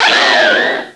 Cri de Gueriaigle dans Pokémon Noir et Blanc.